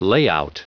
Prononciation du mot layout en anglais (fichier audio)
Prononciation du mot : layout